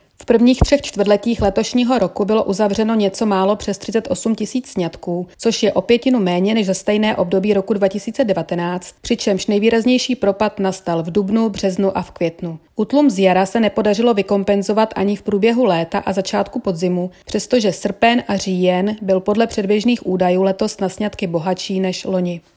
Vyjádření Marka Rojíčka, předsedy ČSÚ, soubor ve formátu MP3, 956.17 kB